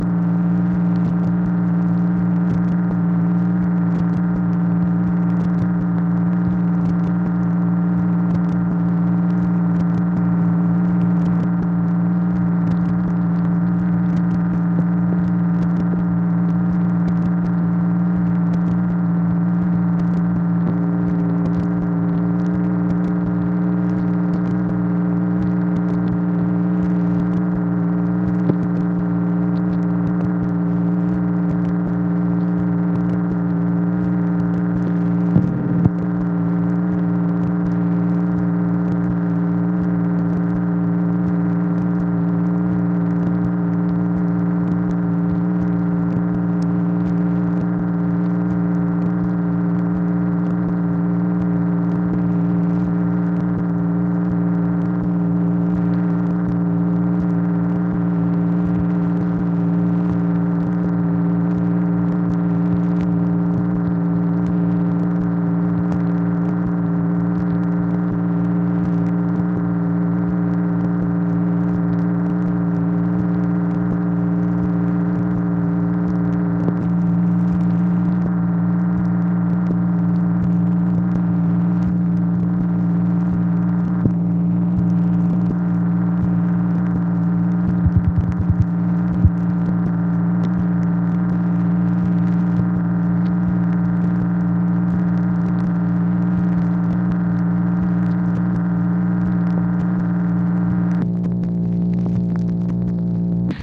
MACHINE NOISE, June 26, 1964
Secret White House Tapes